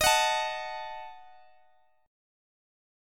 Listen to Ebdim strummed